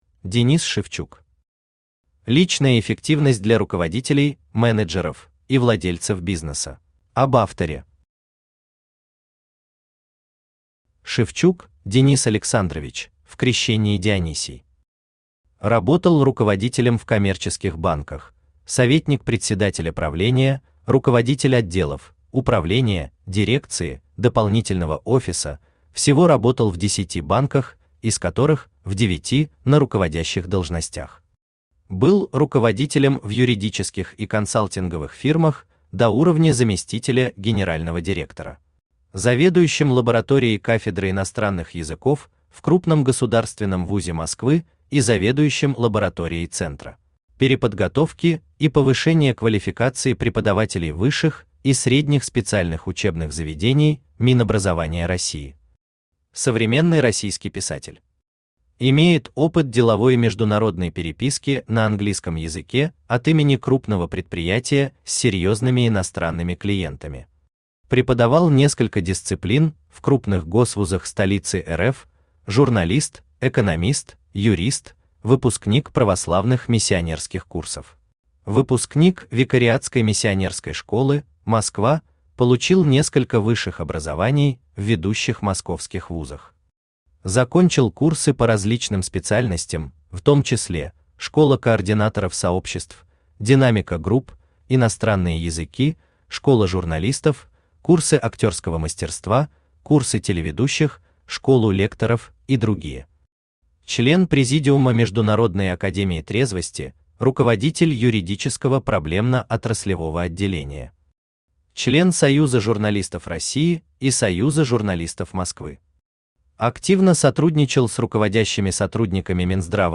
Aудиокнига Личная эффективность для руководителей (менеджеров) и владельцев бизнеса Автор Денис Александрович Шевчук Читает аудиокнигу Авточтец ЛитРес.